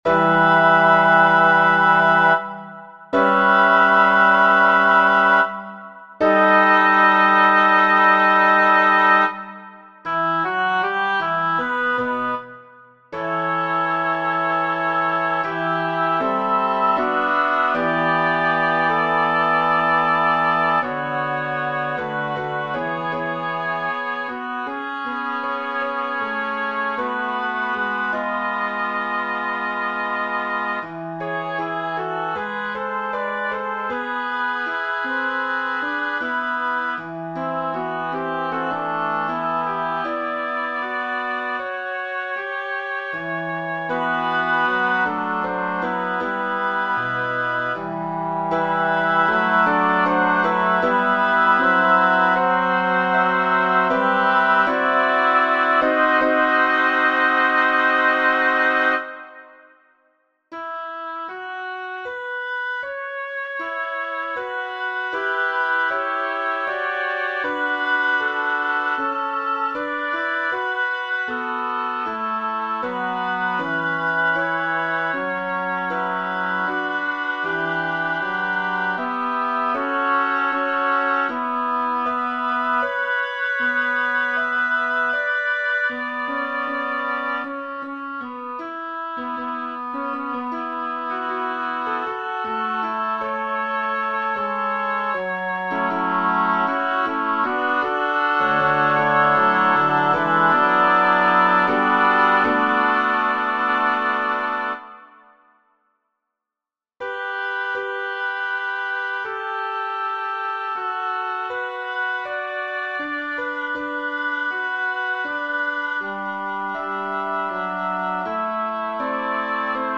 The Abduction of the Coyote - Choral, Vocal - Young Composers Music Forum